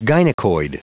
Prononciation du mot gynecoid en anglais (fichier audio)